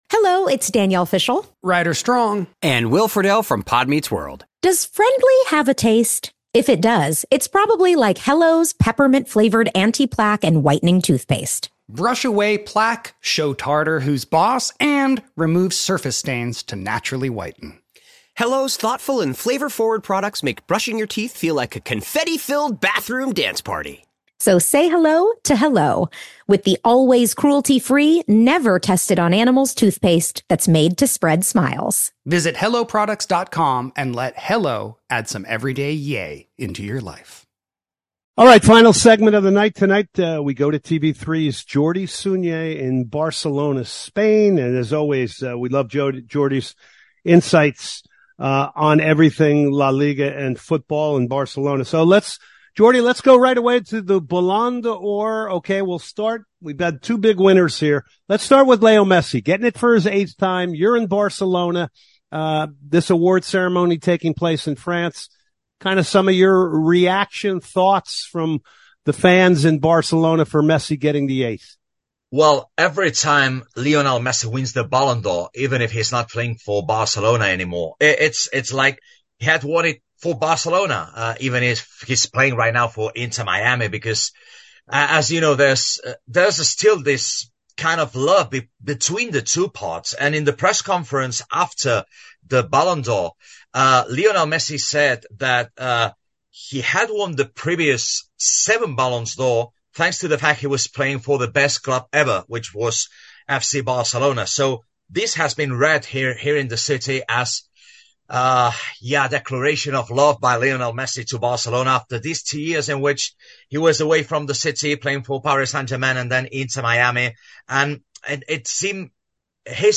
football conversation